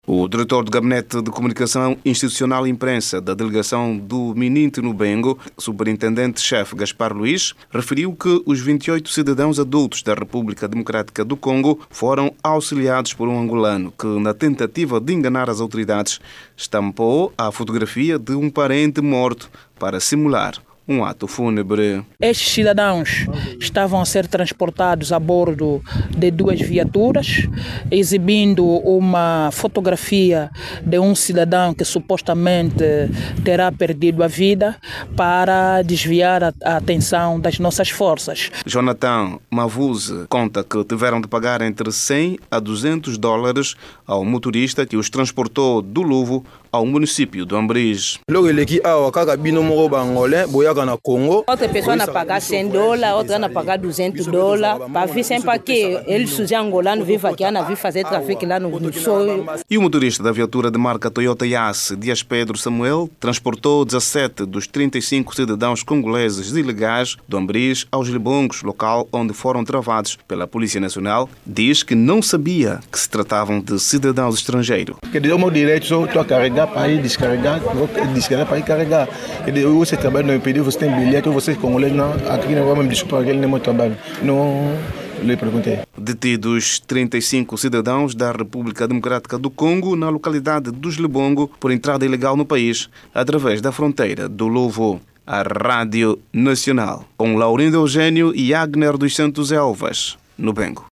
o repórter